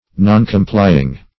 Noncomplying \Non`com*ply"ing\, a. Neglecting or refusing to comply.